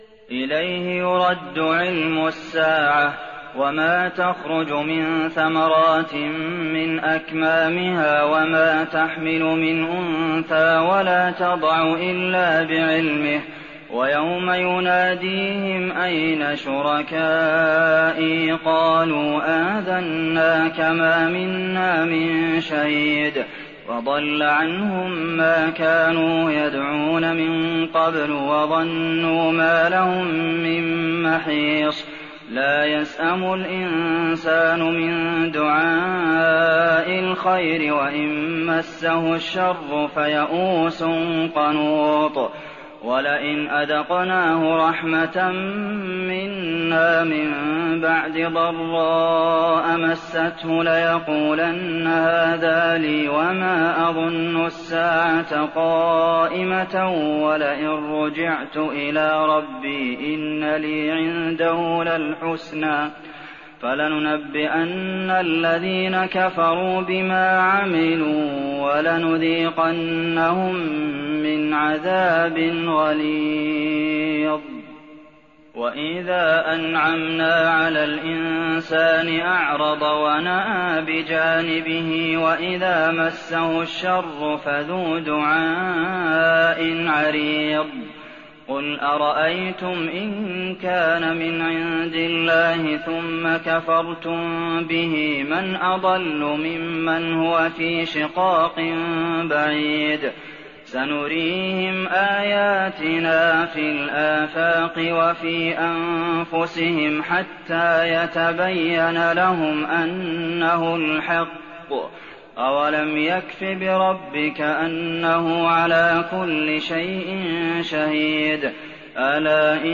تراويح ليلة 24 رمضان 1419هـ من سور فصلت (47-54) و الشورى و الزخرف (1-25) Taraweeh 24th night Ramadan 1419H from Surah Fussilat and Ash-Shura and Az-Zukhruf > تراويح الحرم النبوي عام 1419 🕌 > التراويح - تلاوات الحرمين